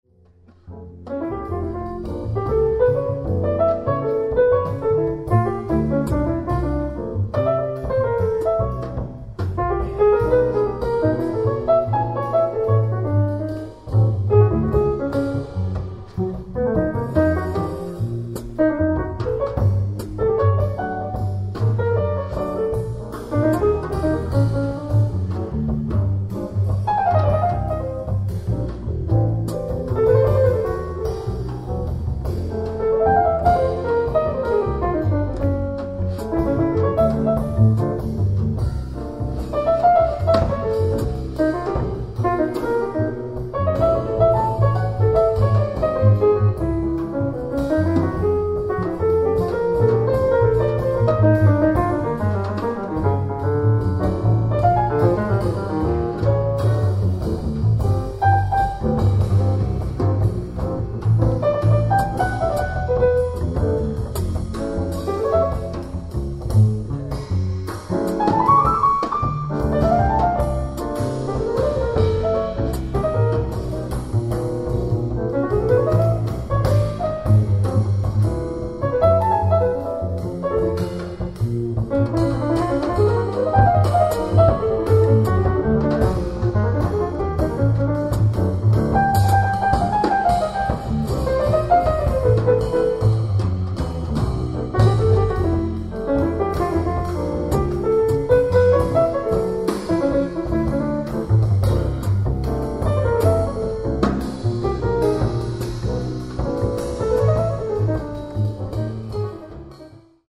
ライブ・アット・ザ・バービカン、ロンドン 06/24/2025
新曲も演奏した２０２５年最新ライブ
※試聴用に実際より音質を落としています。